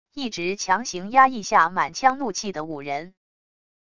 一直强行压抑下满腔怒气的武人wav音频